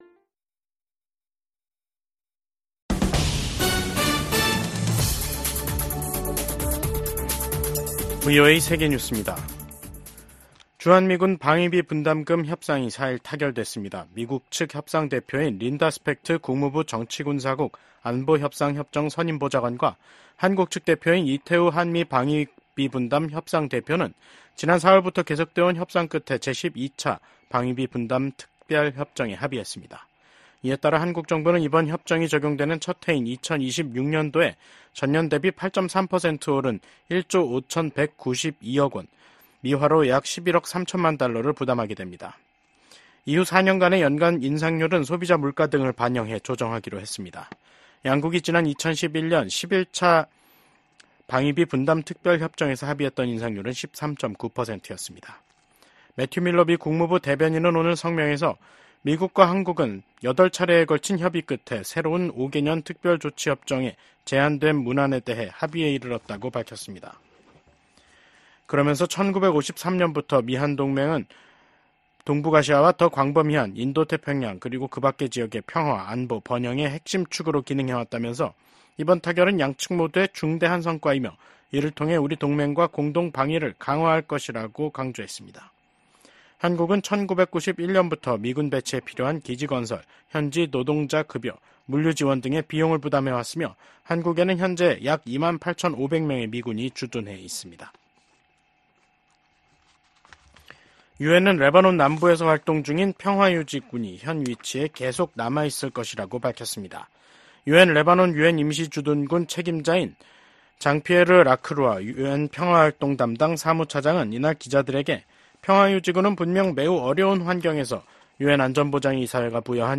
VOA 한국어 간판 뉴스 프로그램 '뉴스 투데이', 2024년 10월 4일 2부 방송입니다. 이시바 시게루 신임 일본 총리가 제안한 ‘아시아판 나토’ 구상에 대해 미국 하원의원들은 대체로 신중한 반응을 보였습니다. 김정은 북한 국무위원장은 윤석열 한국 대통령을 실명으로 비난하면서 미국과 한국이 북한 주권을 침해하려 할 경우 핵무기로 공격하겠다고 위협했습니다.